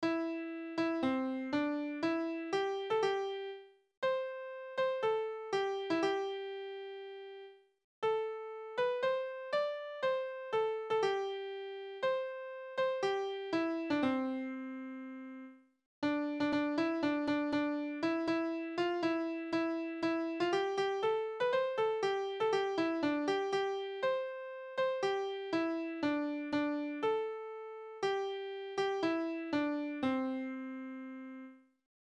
※亀井小愛唱歌（楽譜をもとに復元）.mp3